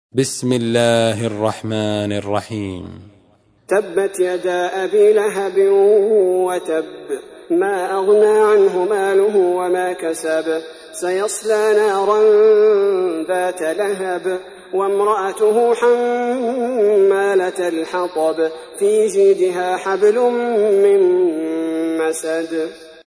سورة المسد / القارئ عبد البارئ الثبيتي / القرآن الكريم / موقع يا حسين